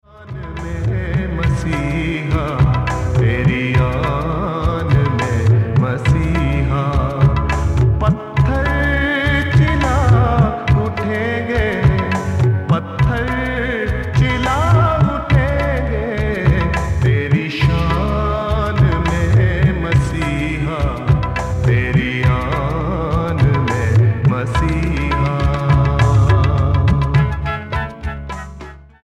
STYLE: World